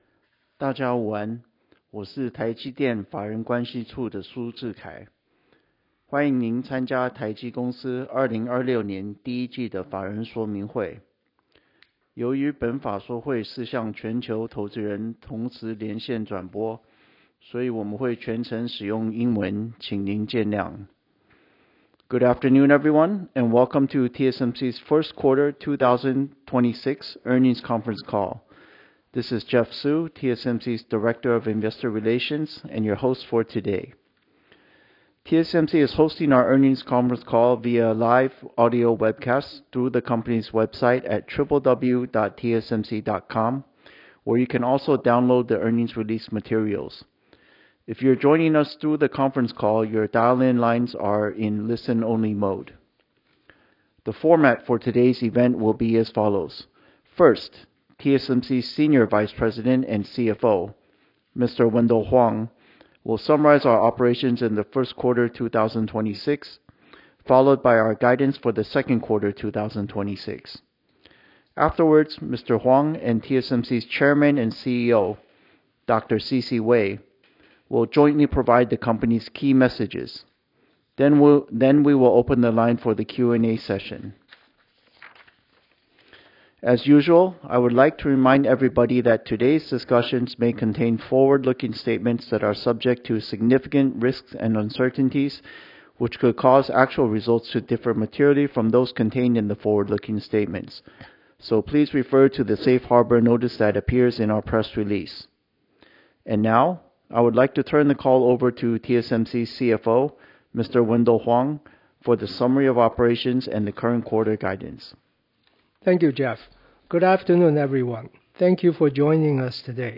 TSMC holds its earnings call today, with tightness at its 3nm node coming into focus as the foundry giant outlines new plans to expand N3 capacity.